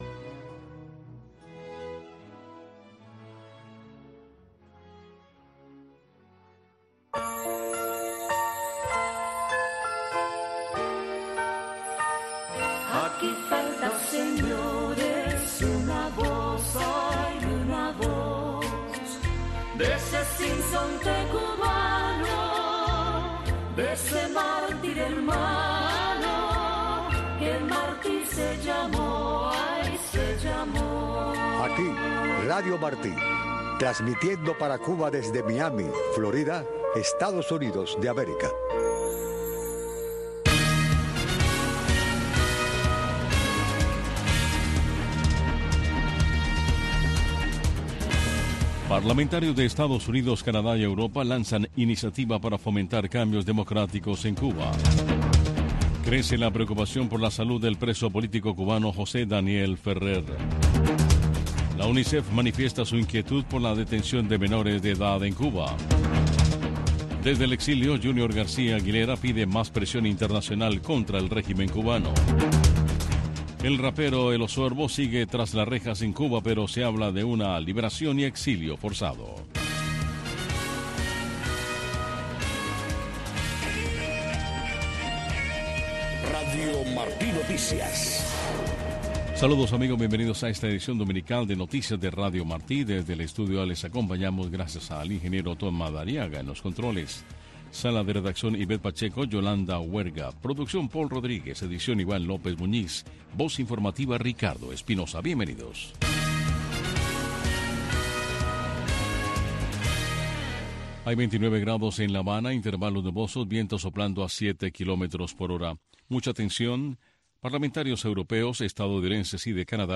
Noticiero de Radio Martí